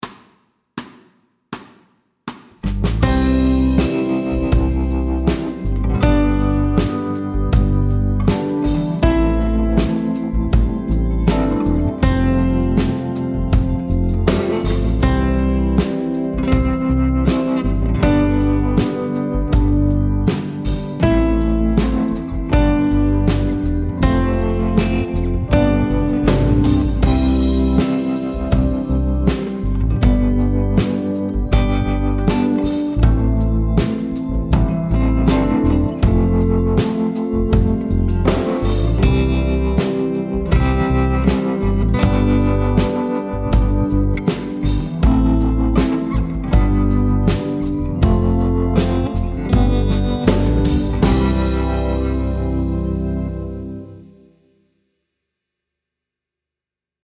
Fast C instr (demo)
I dette afsnit skal du læse og spille 3 toner: C, D og E.
Rytmeværdier: 1/1- og 1/2 noder.